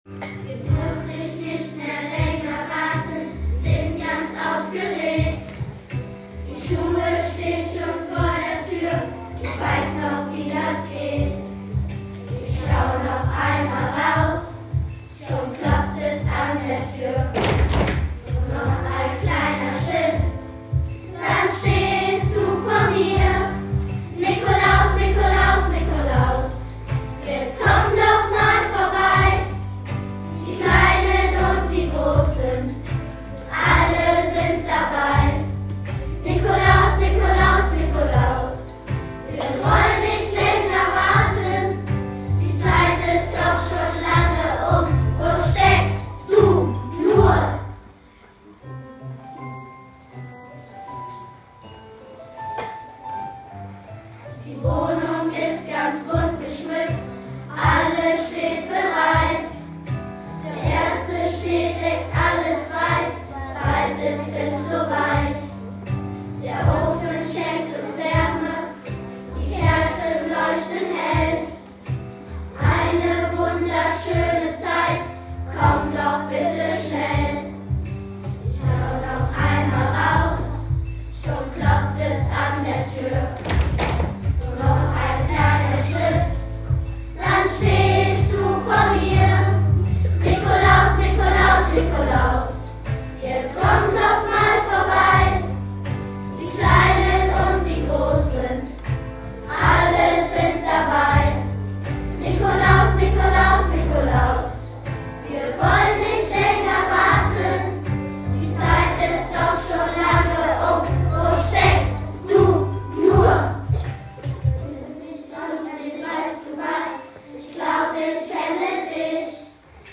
Nach einer kleinen Feier in der Aula mit tollen Beiträgen der Kinder haben wir so schön gesungen, dass tatsächlich der Nikolaus gekommen ist!